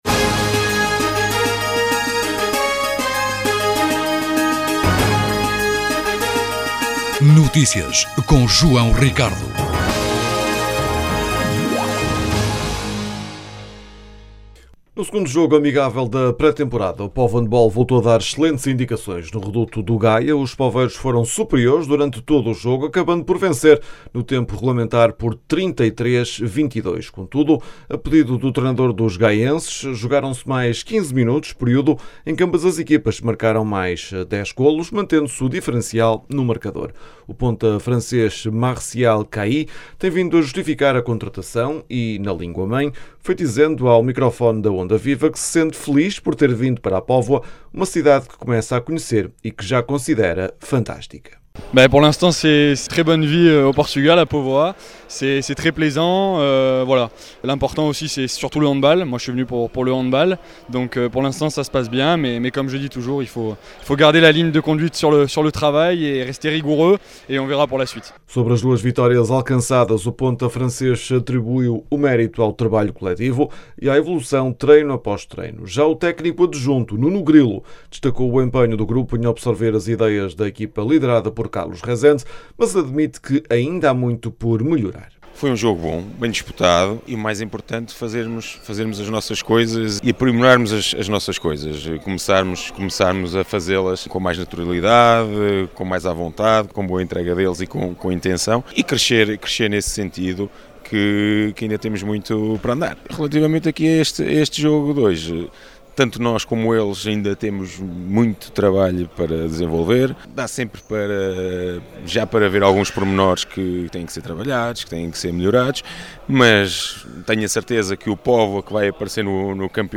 Com esta vitória, a equipa poveira reforça a confiança para os próximos desafios. As declarações podem ser ouvidas na edição local.